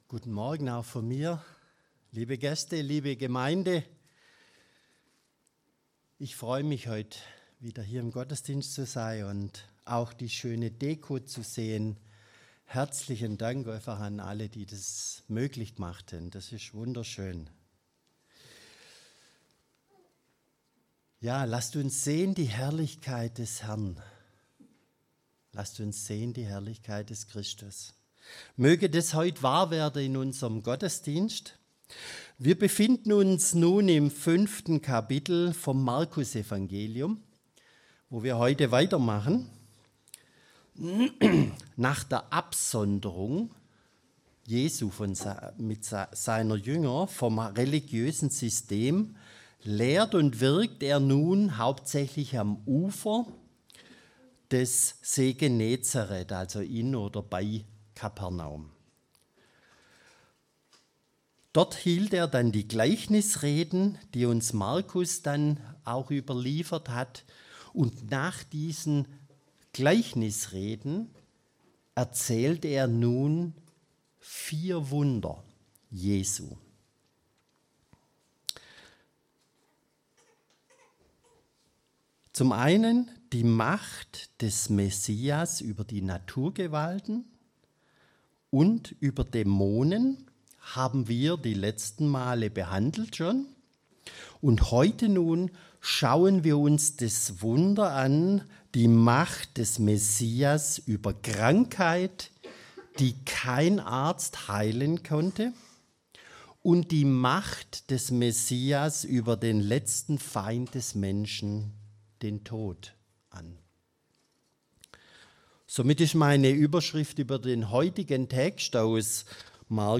Predigtreihe: Markusevangelium Auslegungsreihe